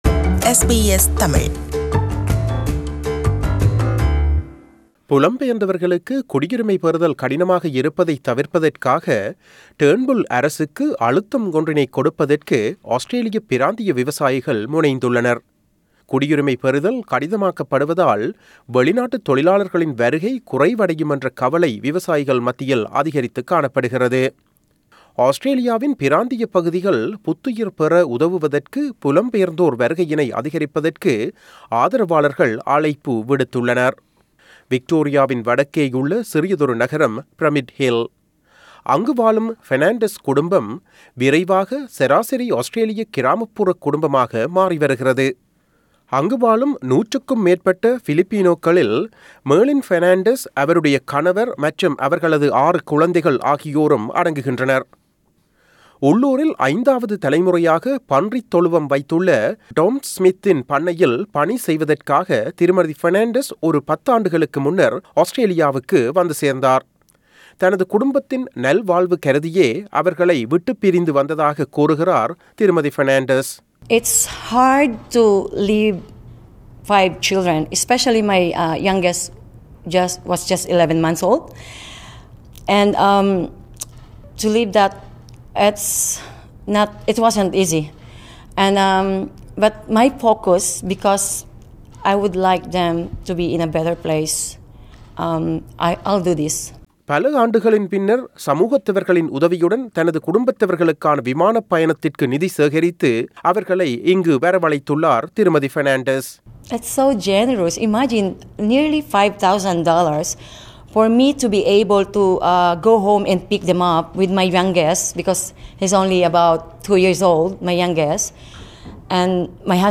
செய்தி விவரணத்தைத்